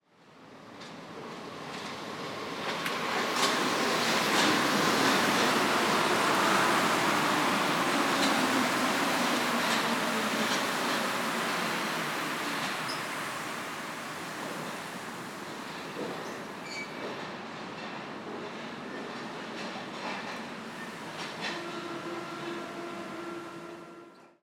Звуки железнодорожного вокзала
Атмосфера станции метро, приближение поезда, люди вдали